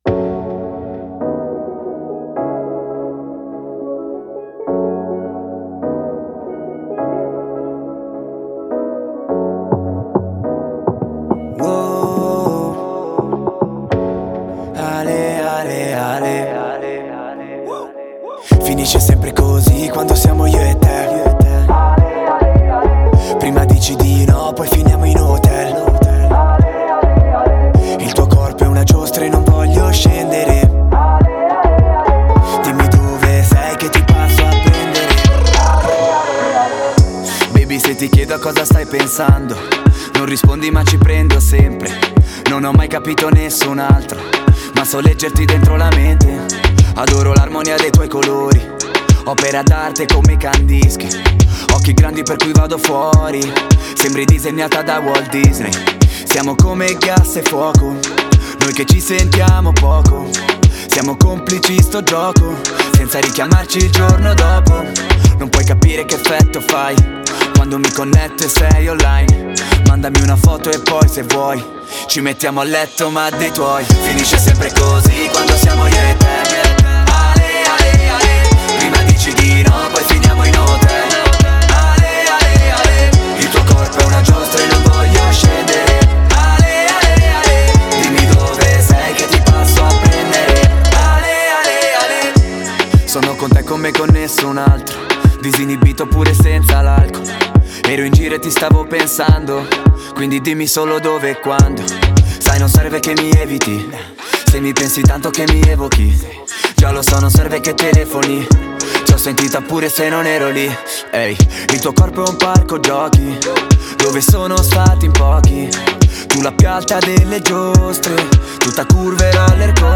Genre: Tamil